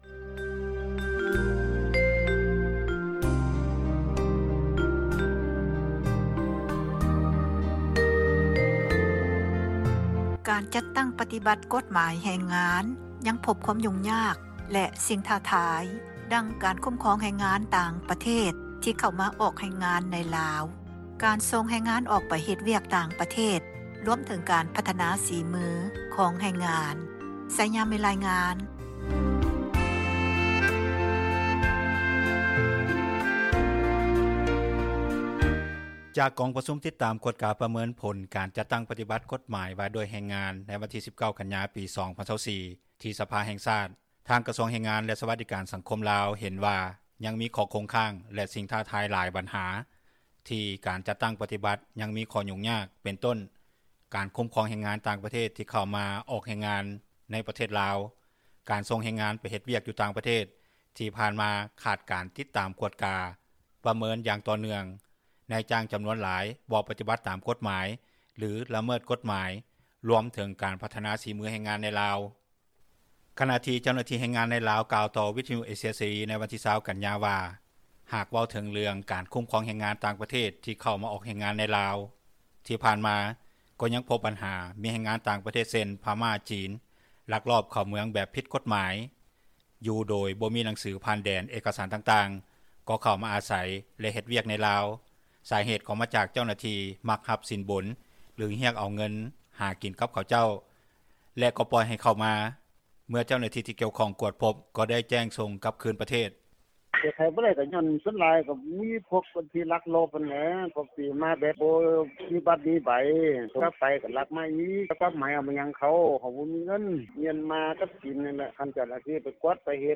ການຈັດຕັ້ງປະຕິບັດ ກົດໝາຍແຮງງານລາວ ຍັງພົບຄວາມຫຍຸ້ງຍາກ – ຂ່າວລາວ ວິທຍຸເອເຊັຽເສຣີ ພາສາລາວ
ຂະນະທີ່ເຈົ້າໜ້າທີ່ແຮງງານໃນລາວ ກ່າວຕໍ່ວິທຍຸເອເຊັຽເສຣີ ໃນວັນທີ 20 ກັນຍາວ່າ ຫາກເວົ້າເຖິງເລື່ອງການຄຸ້ມຄອງແຮງງານຕ່າງປະເທດ ທີ່ເຂົ້າມາອອກແຮງງານໃນລາວທີ່ຜ່ານມາກໍ່ຍັງພົບບັນຫາ ມີແຮງງານຕ່າງປະເທດເຊັ່ນ ພະມ້າ, ຈີນ ລັກລອບເຂົ້າເມືອງແບບຜິດກົດໝາຍຢຸ່ໂດຍບໍ່ມີໜັງສືຜ່ານແດນ ເອກະສານຕ່າງໆ ກໍ່ເຂົ້າມາອາໃສແລະເຮັດວຽກໃນລາວ.